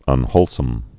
(ŭn-hōlsəm)